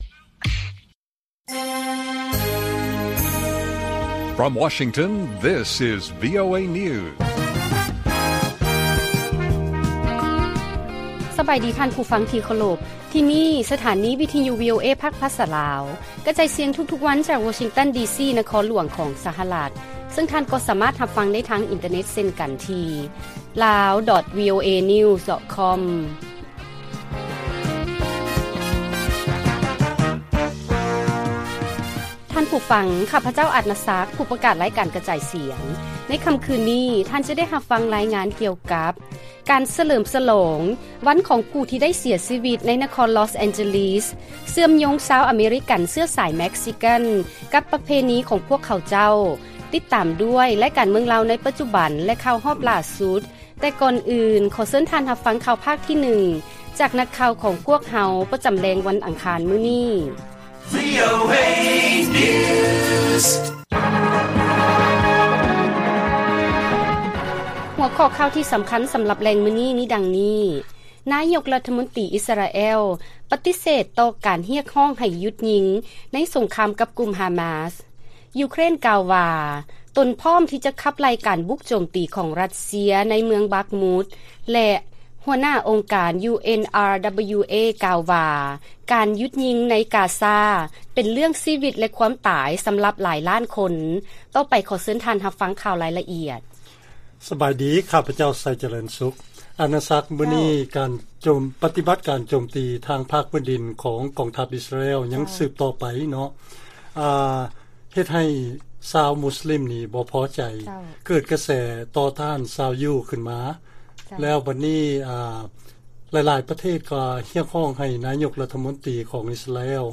ລາຍການກະຈາຍສຽງຂອງວີໂອເອ ລາວ: ນາຍົກລັດຖະມົນຕີອິສຣາແອລ ປະຕິເສດຕໍ່ການຮຽກຮ້ອງໃຫ້ຢຸດຍິງ